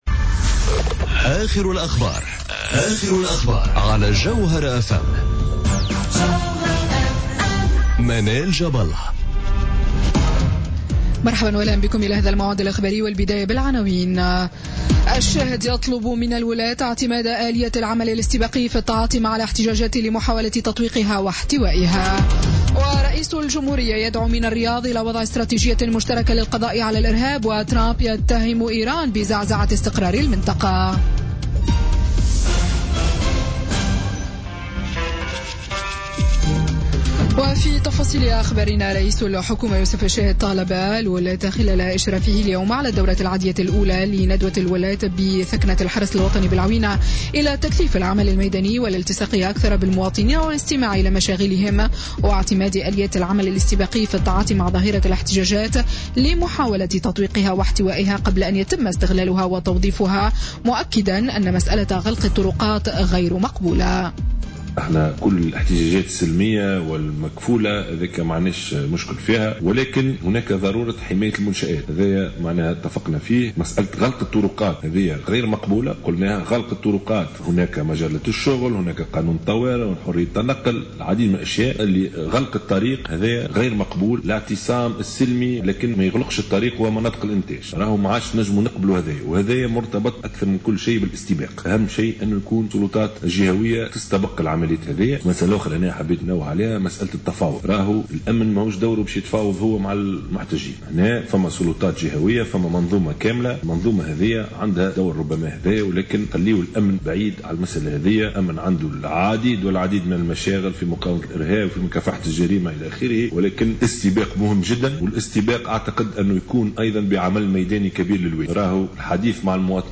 نشرة أخبار السابعة مساء ليوم الأحد 21 ماي 2017